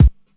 Kick Rnb 8.wav